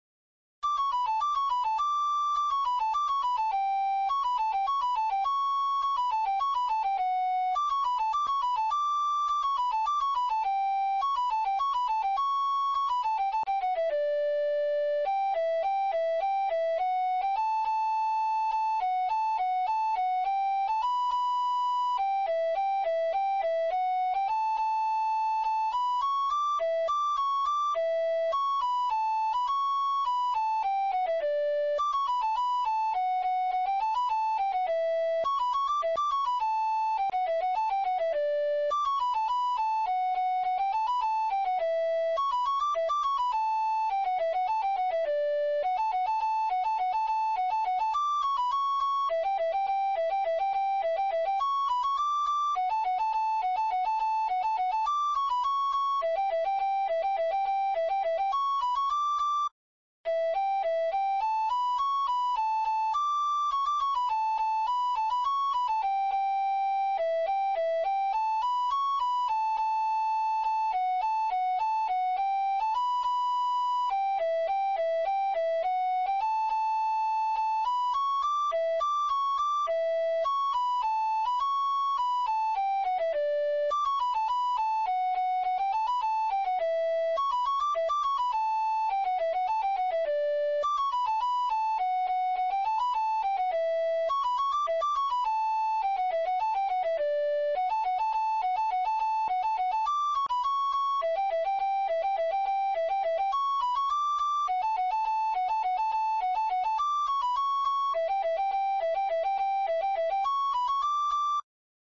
Xotas – Pezas para Gaita Galega
Primeira voz
Segunda voz
Dúo